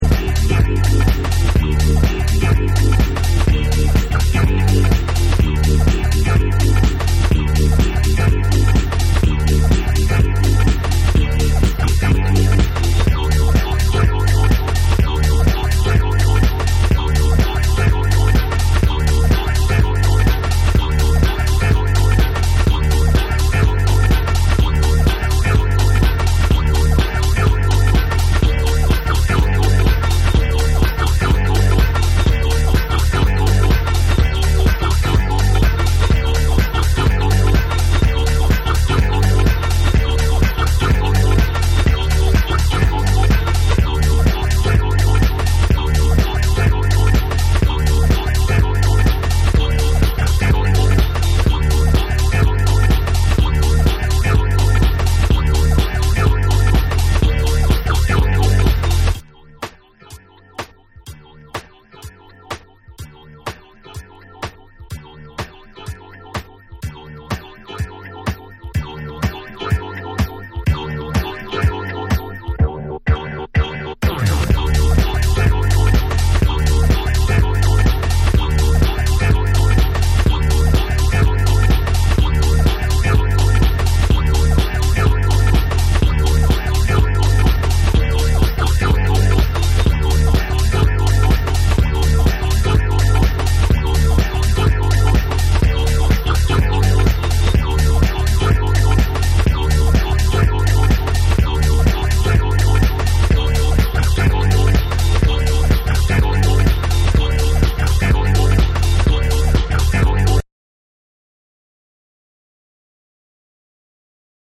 ディスコ・ネタを使ったブリージンなハウス・トラックにヴォイスサンプルやフィルターで抑揚をつけた
TECHNO & HOUSE